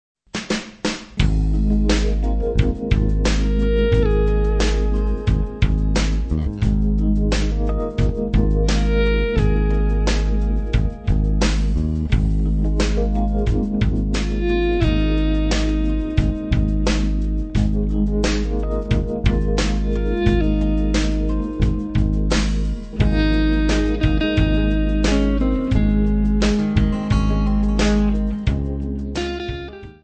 guitar melodies from Poland